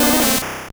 Cri de Mystherbe dans Pokémon Or et Argent.